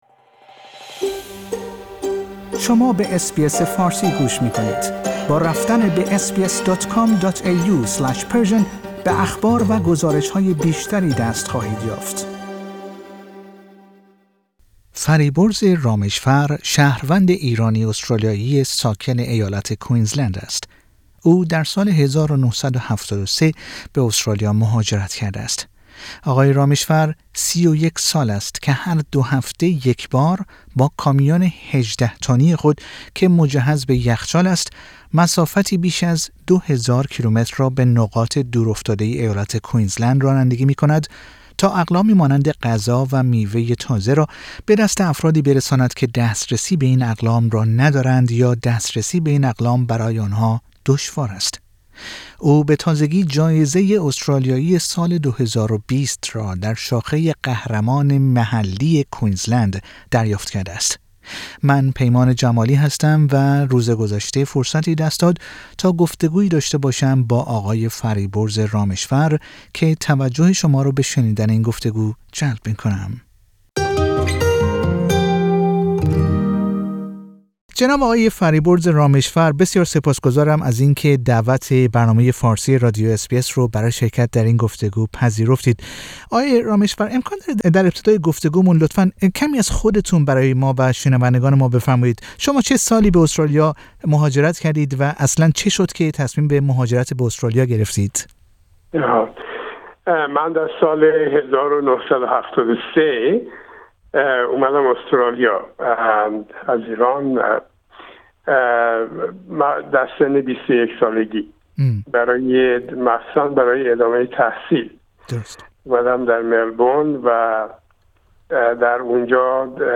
او در گفتگو با رادیو اس بی اس فارسی از خود و دلیلی که او را بر آن داشته تا ظرف ۳۱ سال گذشته چنین کاری را انجام دهد، سخن می گوید.